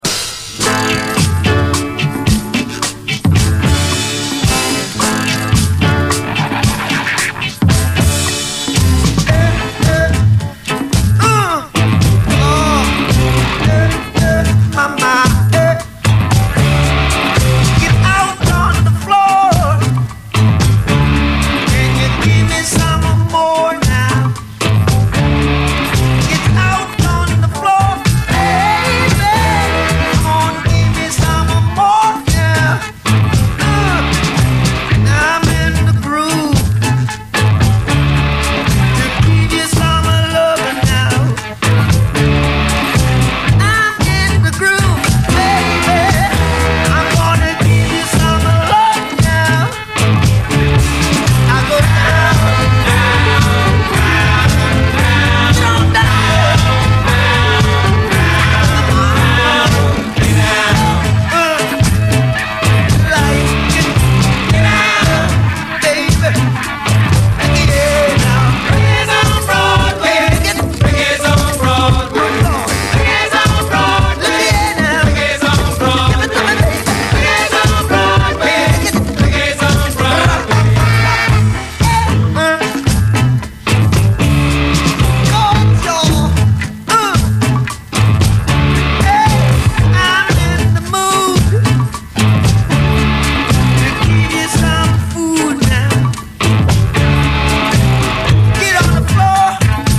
DISCO, REGGAE
熱きロッキン・ディスコ・レゲエ！LOFTクラシック！
ファンキーかつロッキン、荒々しくギンギンにギターが鳴り響くLOFTクラシック！